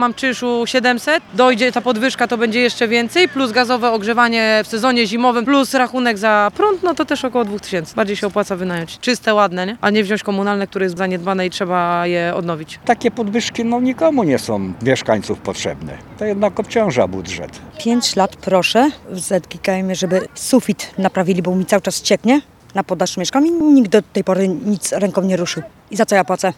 Będzie podwyżka czynszu za lokale komunalne i socjalne w Malborku. Mieszkańcy komentują [POSŁUCHAJ]